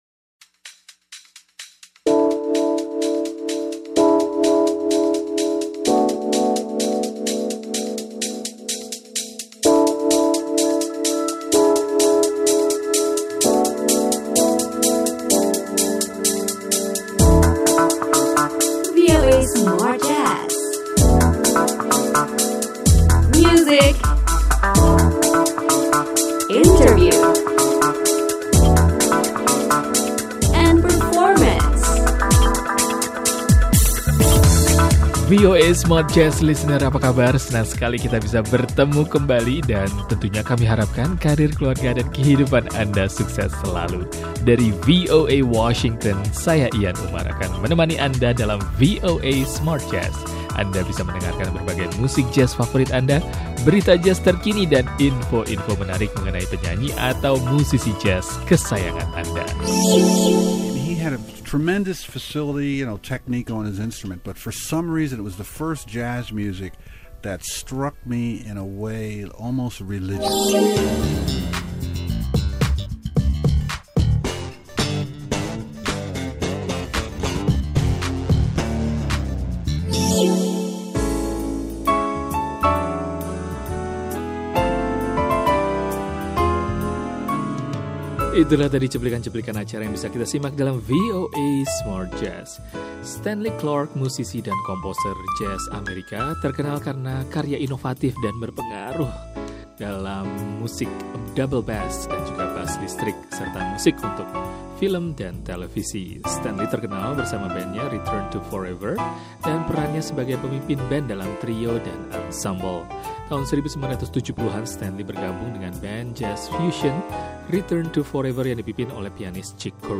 musisi dan komposer jazz Amerika
perbincangan
Grup jazz